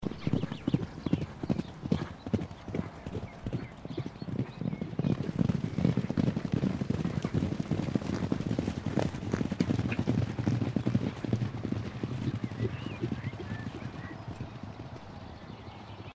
割草
描述：割草。
Tag: 双脚 击打 步行 奔跑 草地 脚步